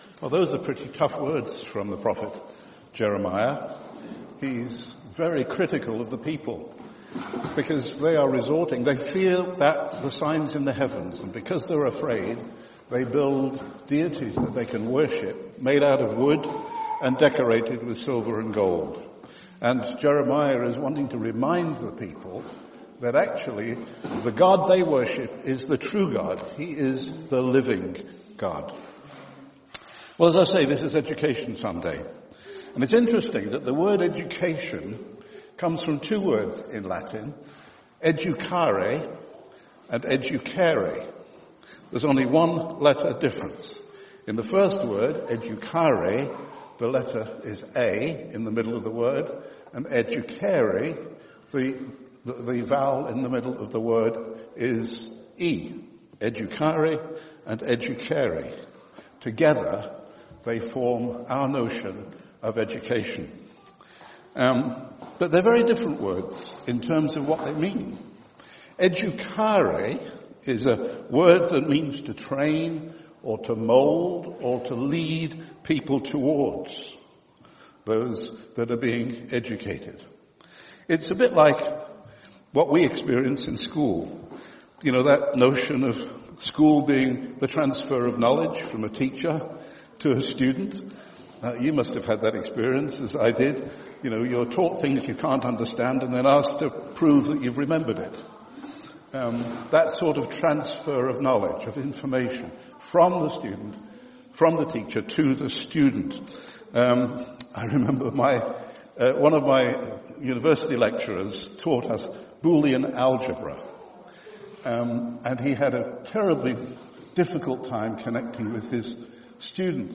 Teach me Thy way: a sermon in two parts